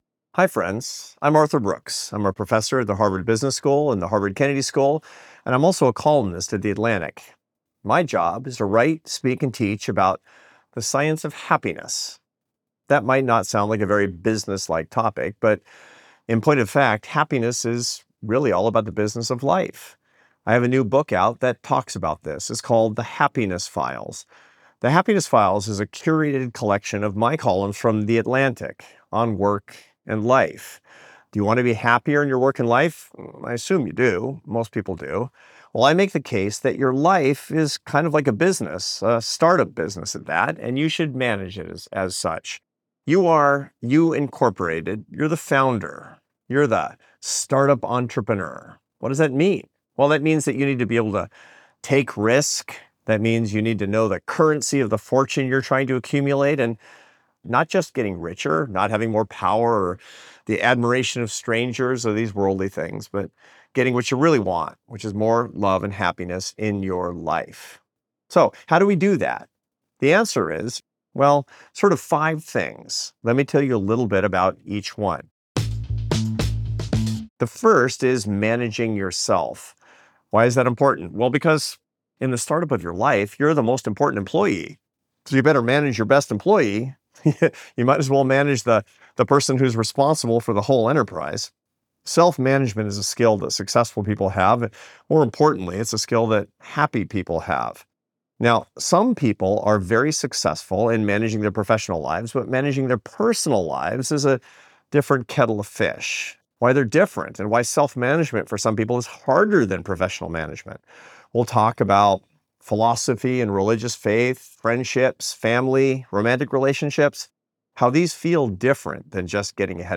Listen to the audio version—read by Arthur himself—below, or in the Next Big Idea App.